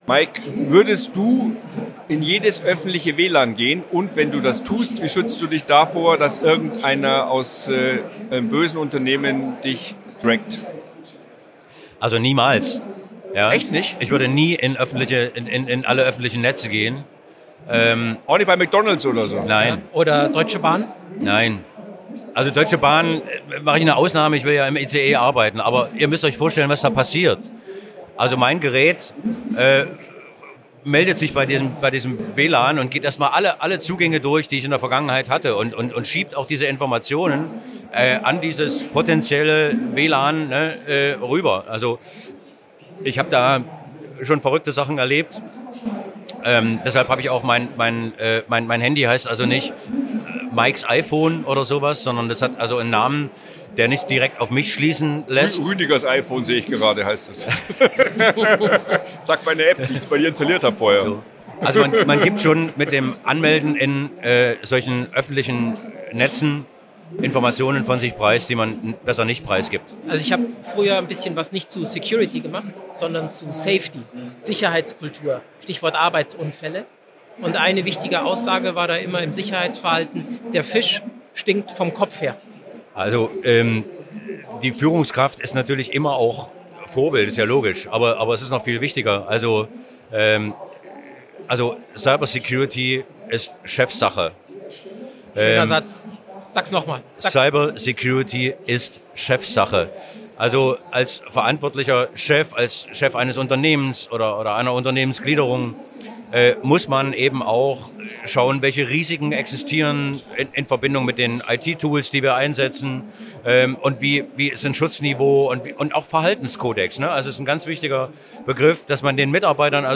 sprechen auf der it-sa in Nürnberg